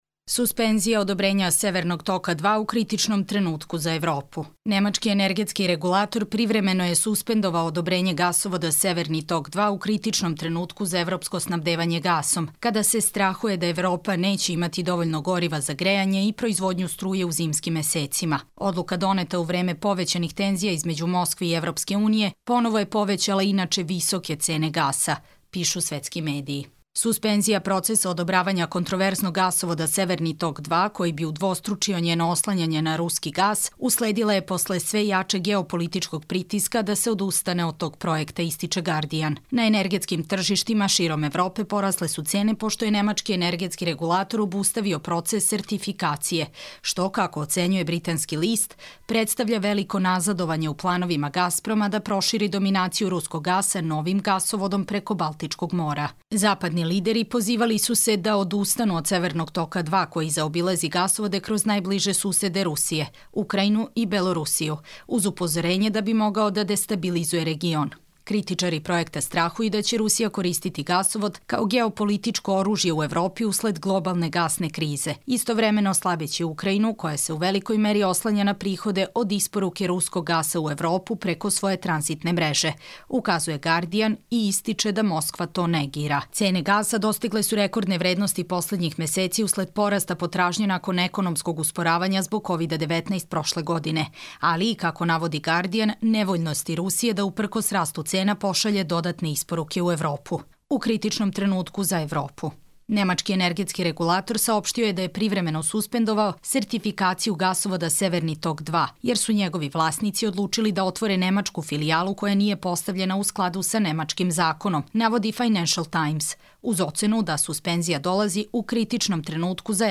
Čitamo vam: Suspenzija odobrenja Severnog toka 2 u kritičnom trenutku za Evropu